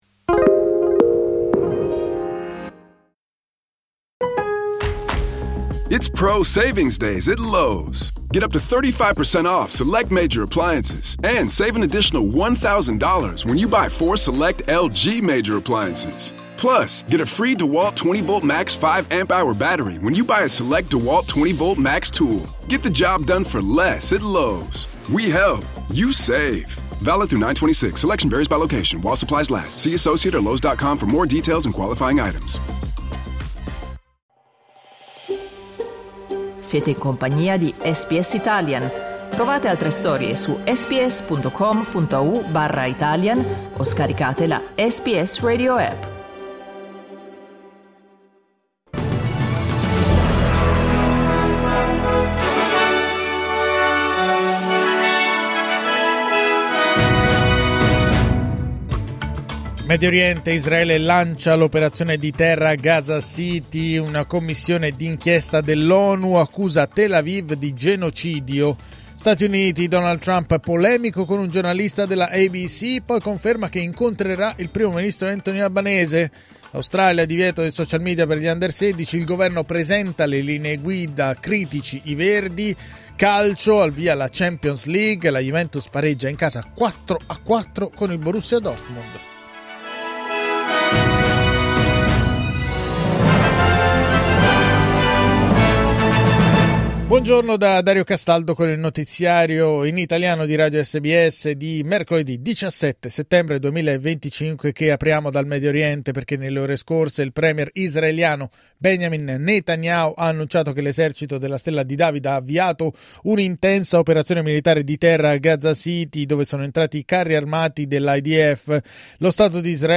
Giornale radio mercoledì 17 settembre 2025
Il notiziario di SBS in italiano.